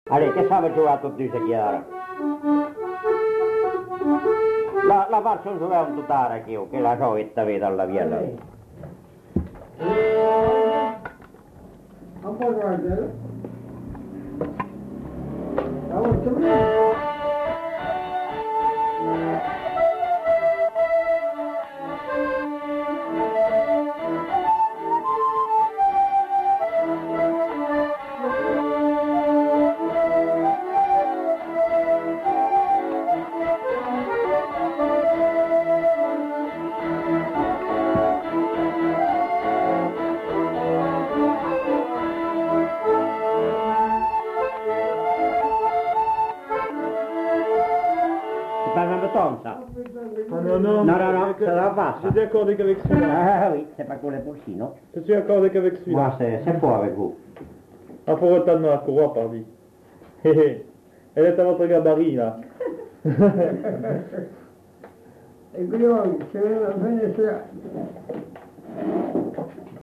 Lieu : Pindères
Genre : morceau instrumental
Instrument de musique : accordéon diatonique ; vielle à roue
Danse : valse